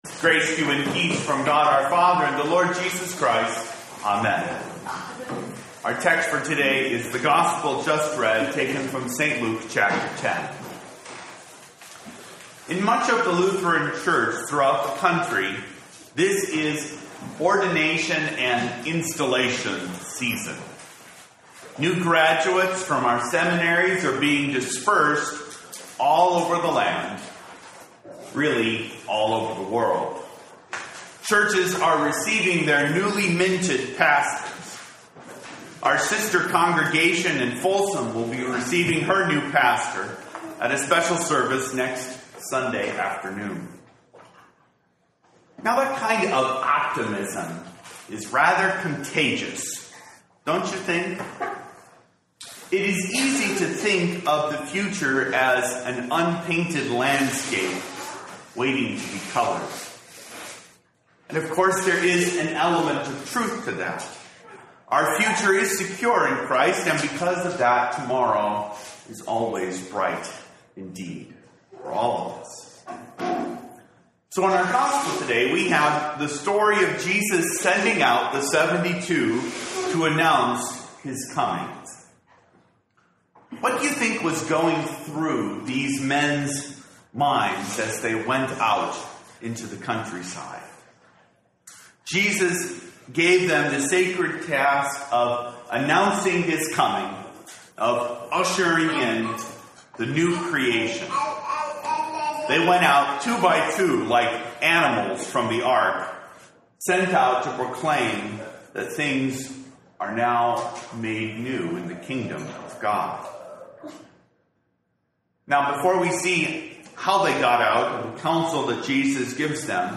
sermon 7-7-2013.mp3